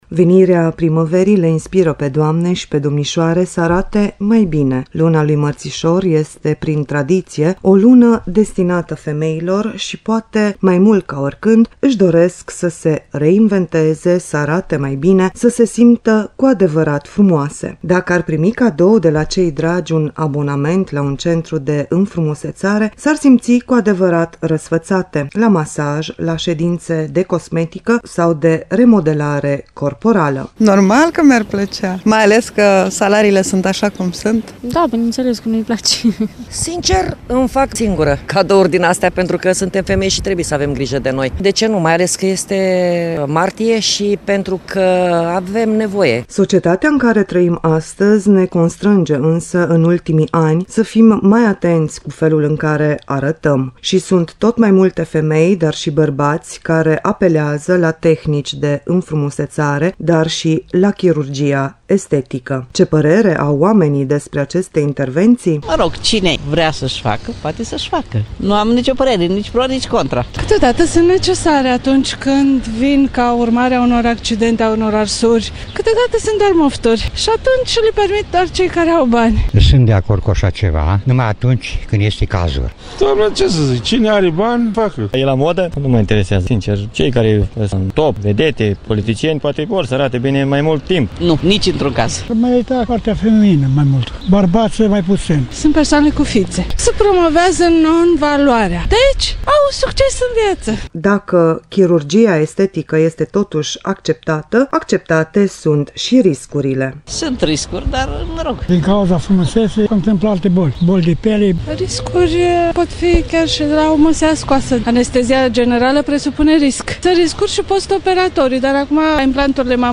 Cum a fost prezentată emisiunea în  preambulul realizat de